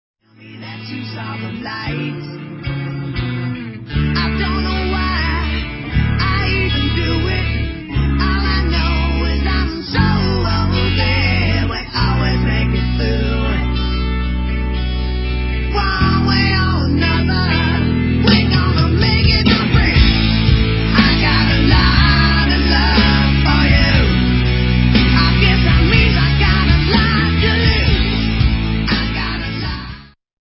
GUITAR
DRUMS
VOCALS
BASS